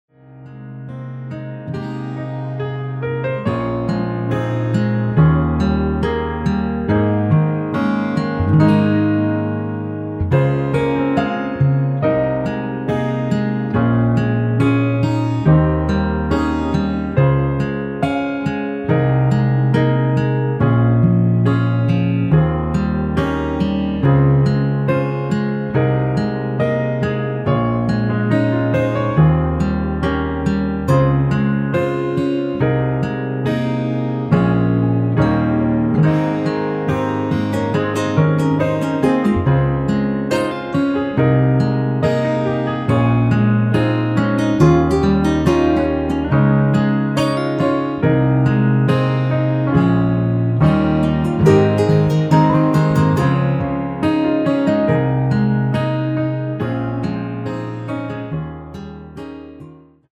(-2) 내린1절후 후렴으로 진행되게 편곡 하였습니다.(아래의 가사 참조)
Ab
◈ 곡명 옆 (-1)은 반음 내림, (+1)은 반음 올림 입니다.
앞부분30초, 뒷부분30초씩 편집해서 올려 드리고 있습니다.